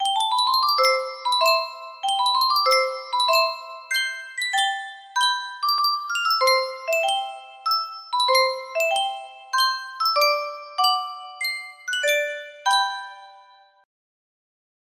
Sankyo Music Box - Faust Waltz CSQ music box melody
Full range 60